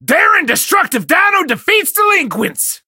buzzilla_lead_vo_04.ogg